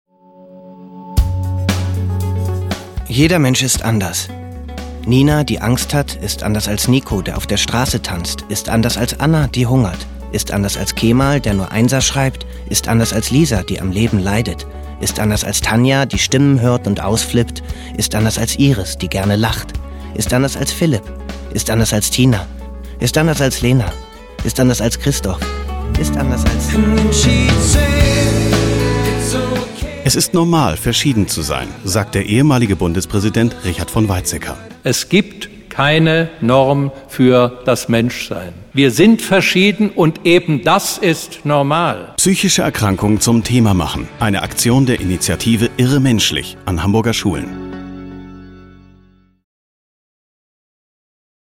Radio Trailer des Vereins